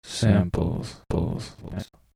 "samples" 2 sec. stereo 45k